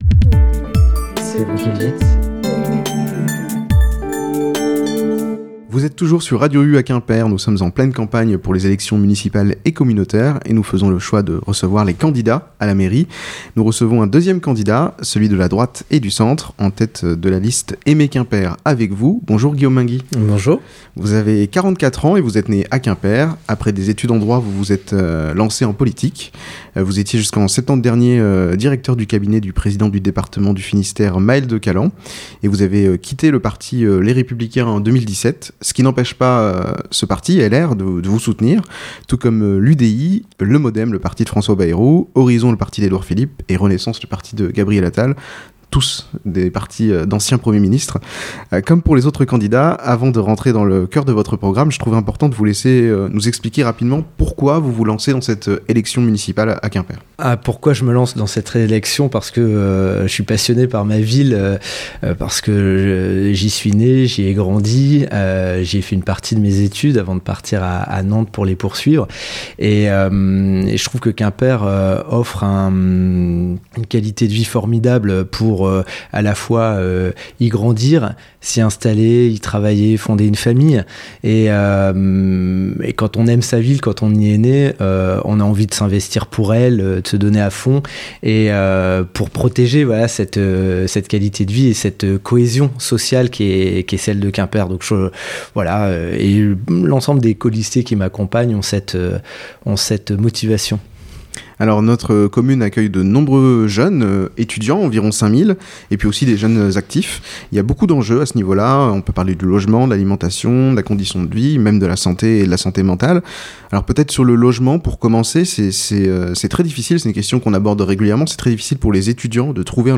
Dans le cadre des élections municipales et communautaires, Radio U fait le choix de recevoir les candidats à la mairie de Quimper.